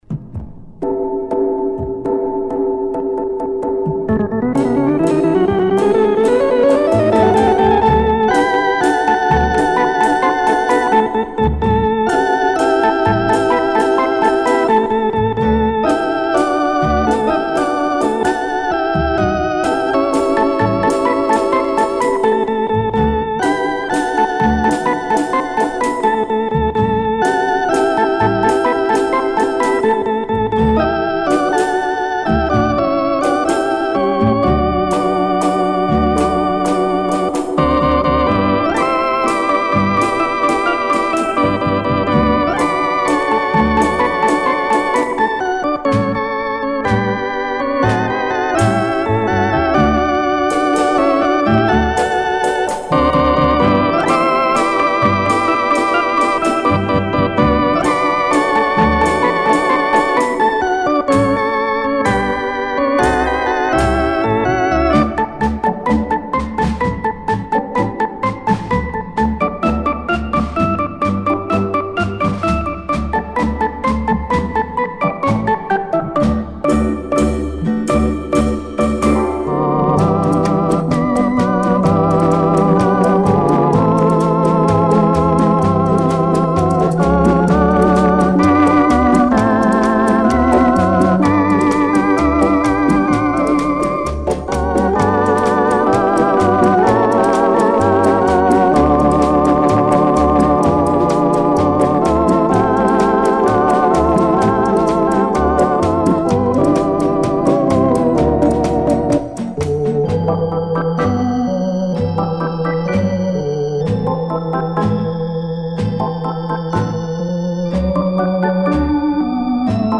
Genre:Easy Listening, Instrumental